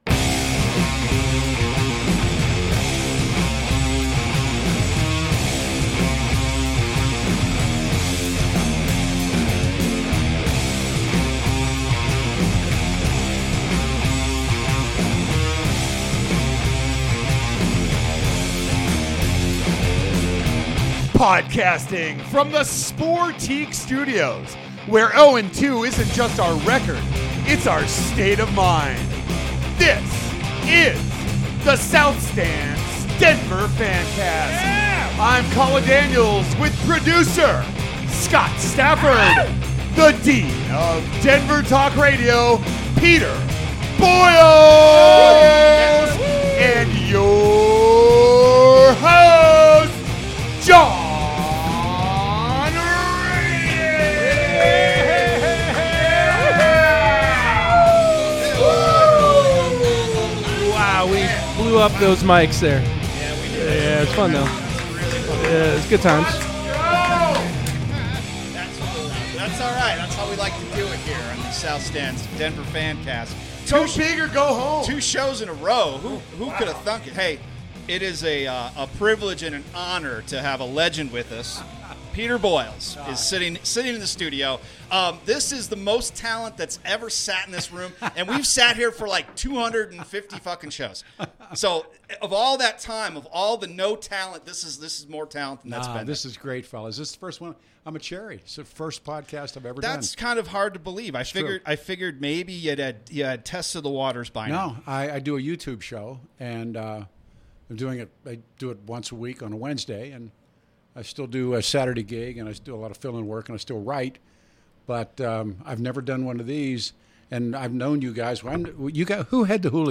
This is the full show if you want it all in one piece, plus a link to the YouTube broadcast that has the bonus talk between the breaks: